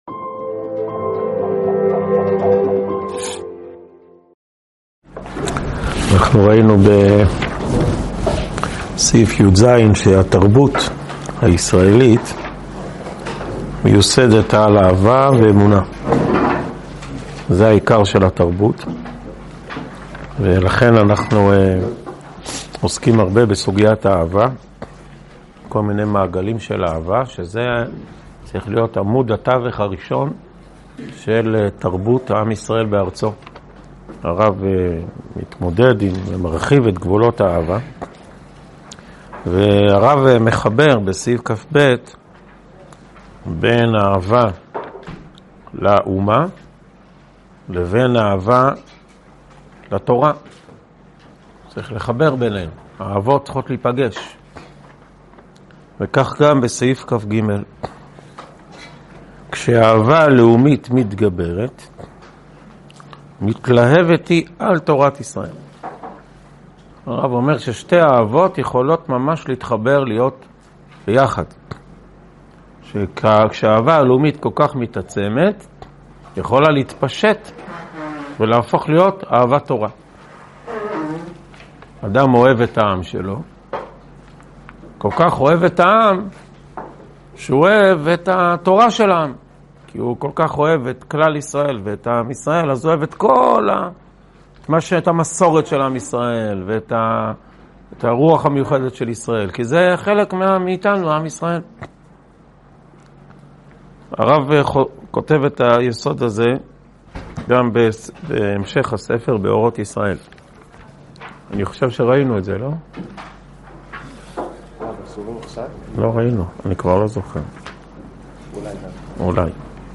הועבר בישיבת אלון מורה בשנת תשפ"ד.